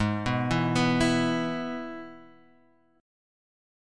Abm#5 chord